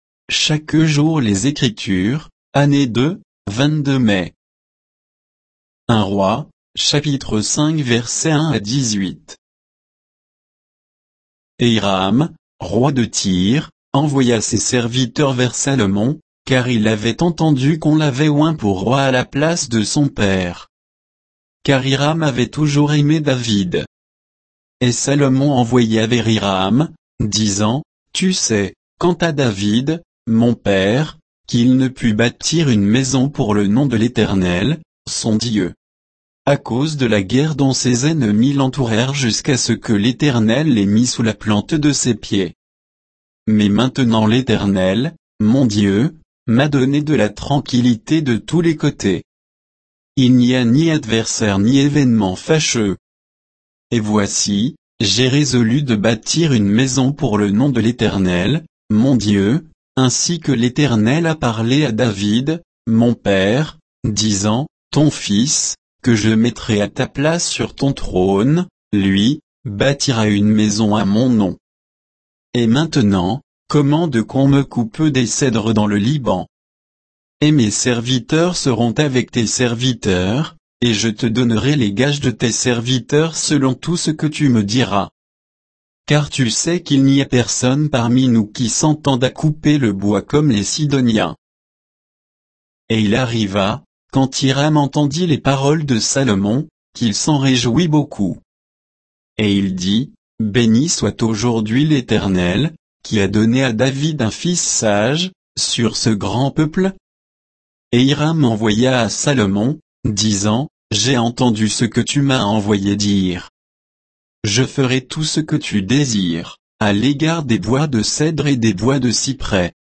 Méditation quoditienne de Chaque jour les Écritures sur 1 Rois 5